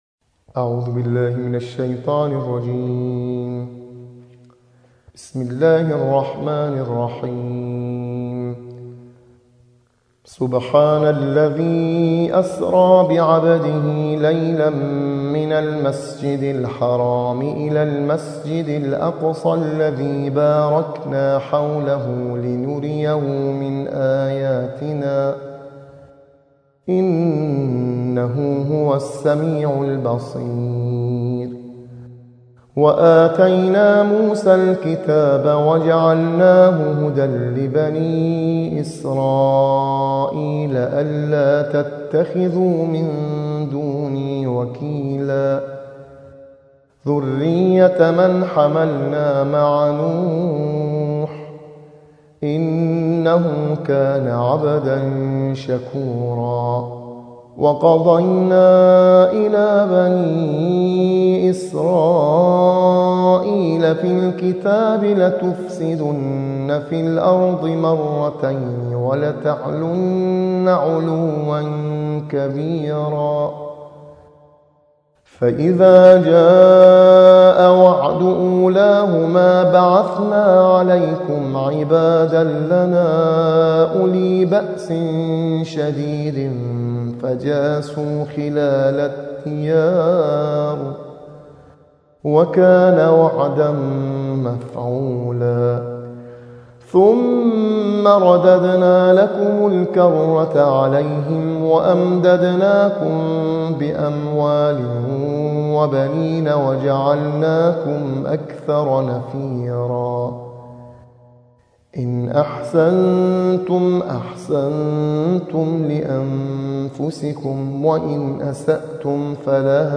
قاری بین‌المللی